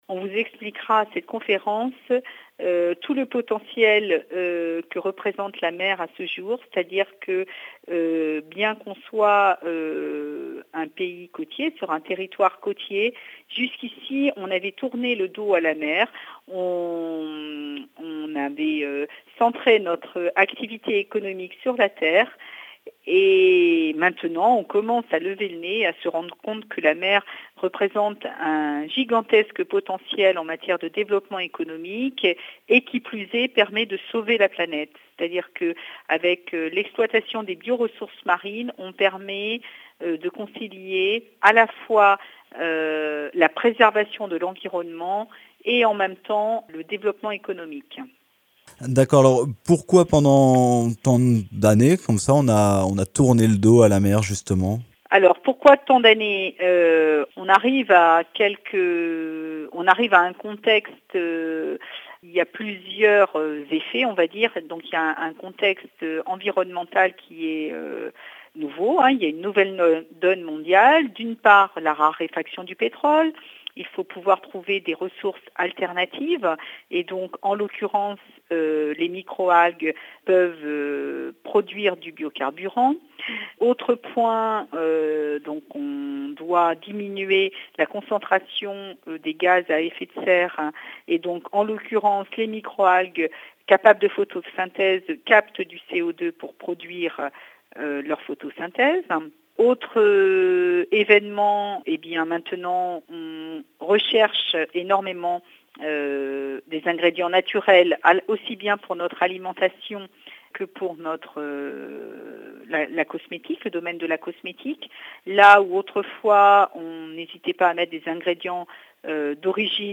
Interview Biomarine: à partir de ce mercredi à Nantes